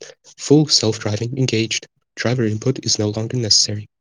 full-self-driving-engaged.wav